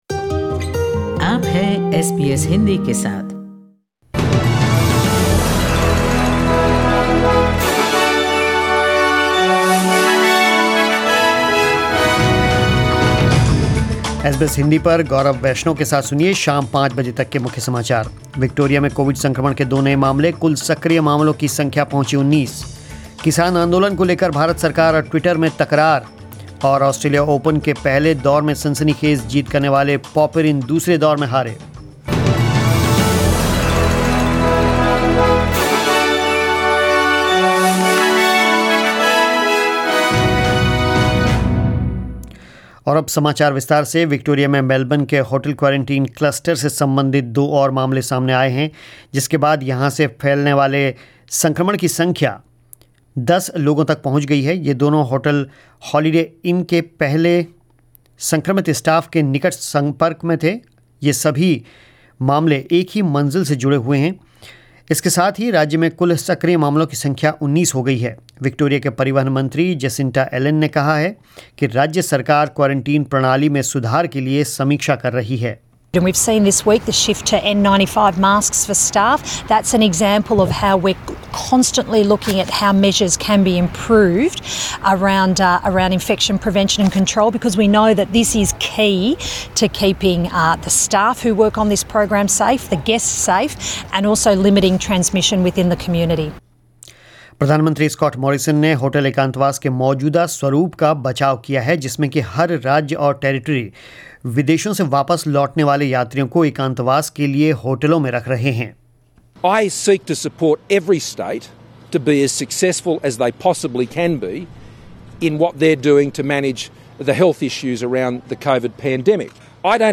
News in Hindi: Tough action if social media misused says Indian Government.